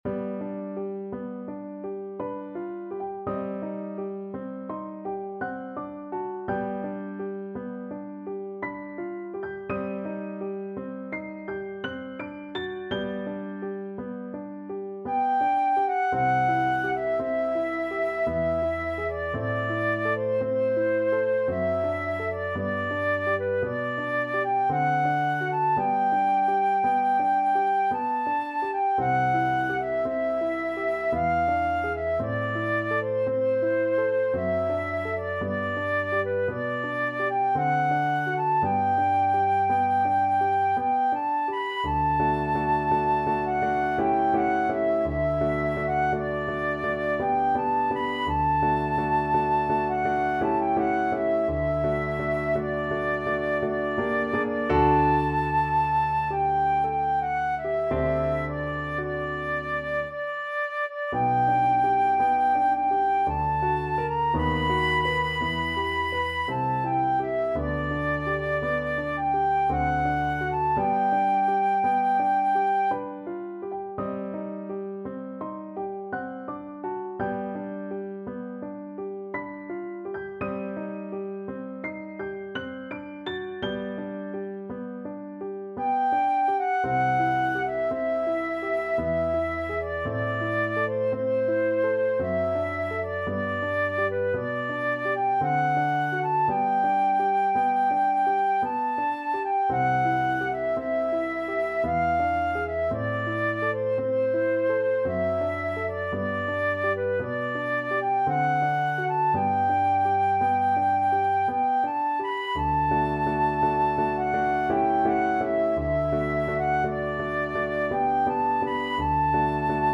Flute
~ = 56 Ziemlich langsam
3/4 (View more 3/4 Music)
G major (Sounding Pitch) (View more G major Music for Flute )
Classical (View more Classical Flute Music)